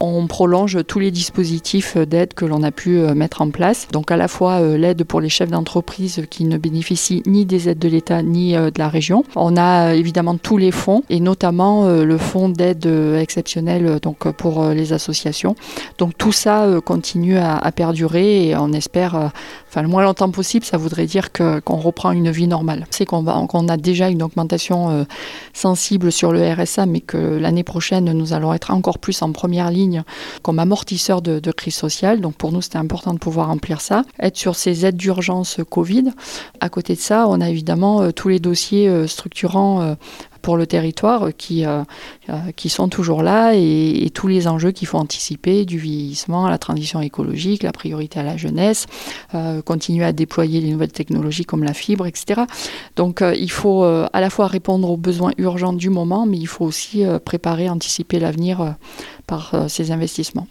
Par ailleurs, les fonds ouverts pendant le premier confinement sont toujours accessibles au premier trimestre 2021, pour les entreprises et associations. Sophie PANTEL présidente du Conseil départemental.